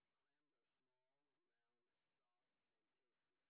sp21_street_snr20.wav